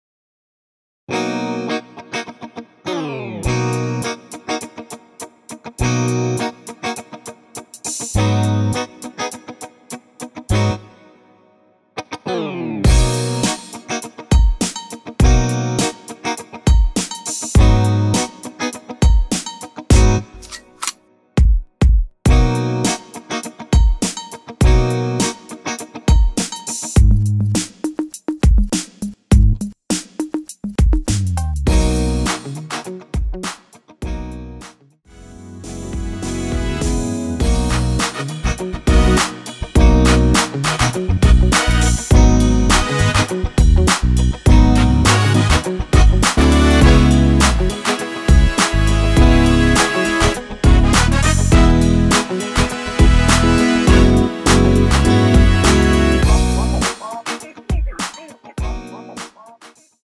전주 없이 시작 하는 곡이라서 1마디 전주 만들어 놓았습니다.(미리듣기 확인)
3초쯤 노래 시작 됩니다.
원키에서(-2)내린 MR입니다.
앞부분30초, 뒷부분30초씩 편집해서 올려 드리고 있습니다.
중간에 음이 끈어지고 다시 나오는 이유는